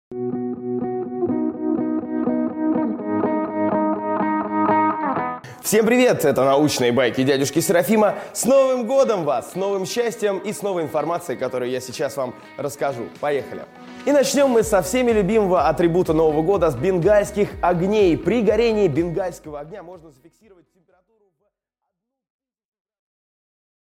Аудиокнига Бенгальские огни | Библиотека аудиокниг